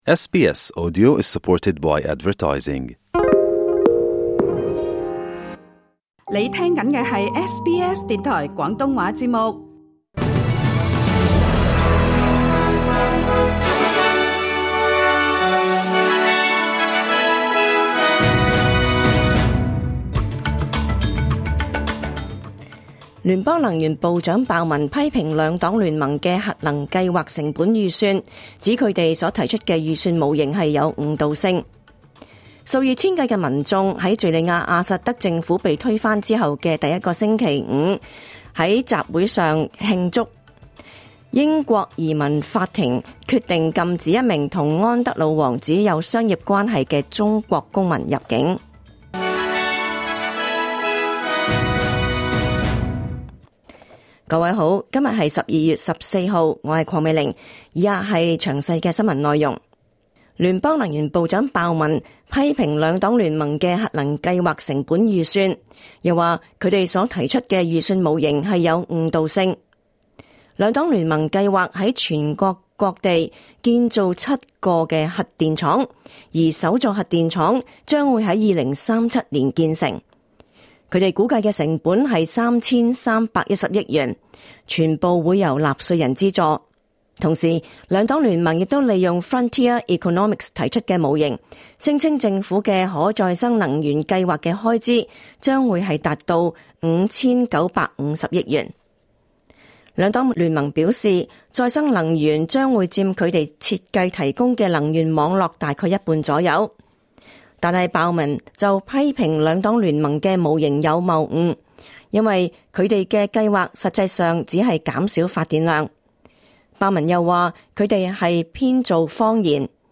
2024 年 12 月 14 日 SBS 廣東話節目詳盡早晨新聞報道。